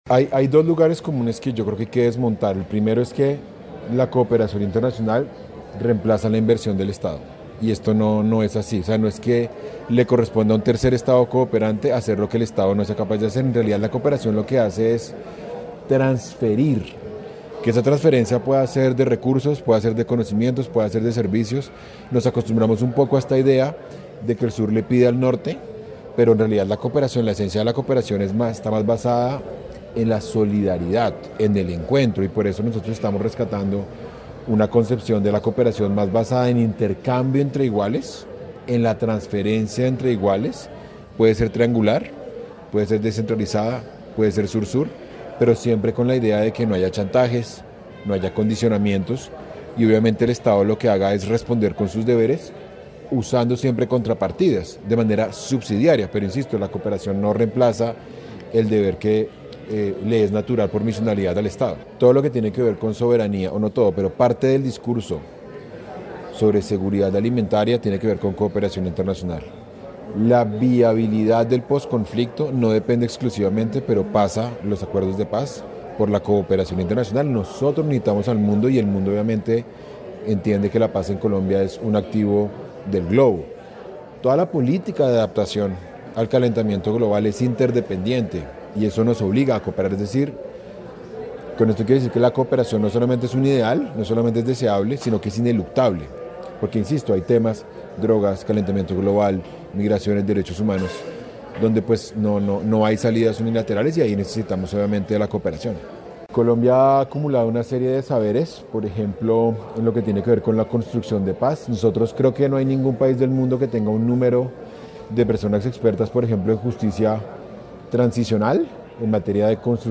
Viceministro de asuntos multilaterales
En el marco del evento de la innovación en la cooperación Sur Sur que se lleva a cabo en el Quindío, El viceministro de asuntos multilaterales del ministerio de relaciones exteriores de Colombia, Mauricio Jaramillo reconoció lo clave de las alianzas para impulsar los diferentes sectores.